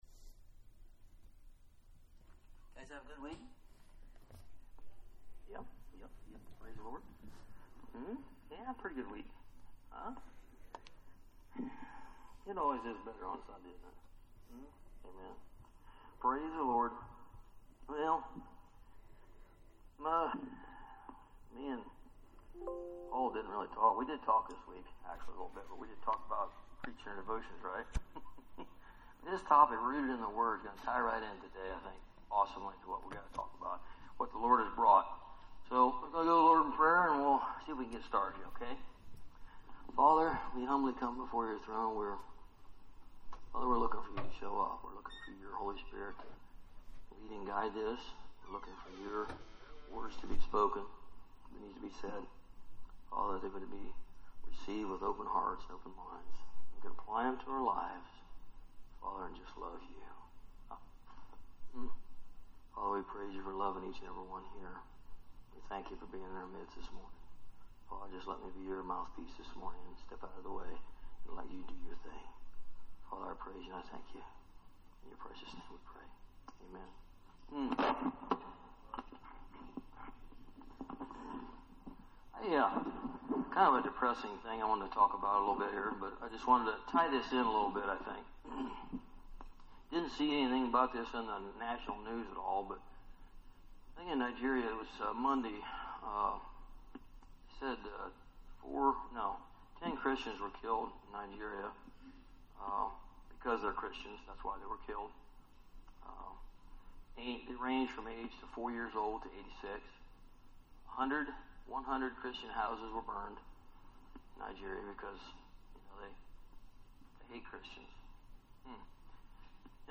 Recited a piece from Charles Spurgeon